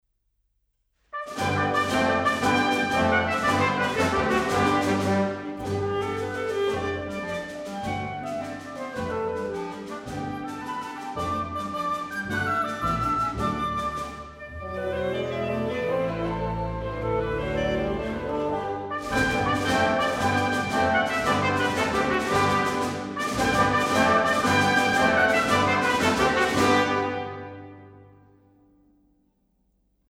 Orchesterwerke verfemter Komponisten